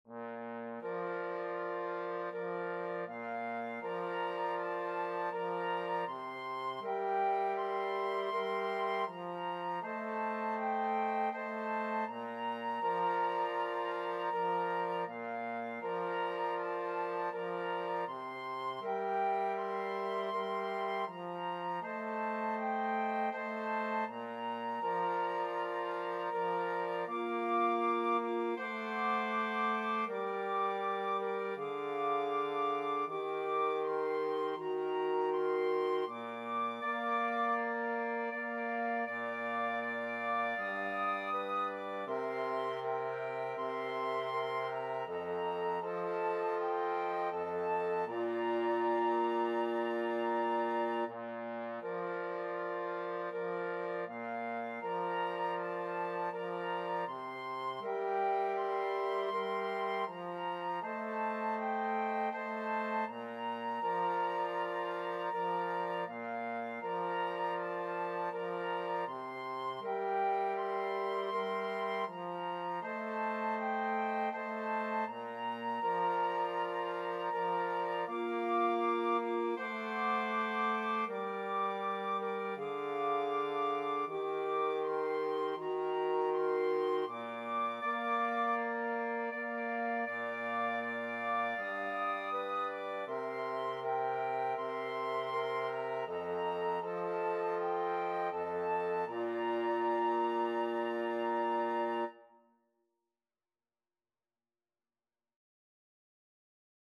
Flute
Clarinet
Alto Saxophone
Trombone
4/4 (View more 4/4 Music)
Andante = c. 80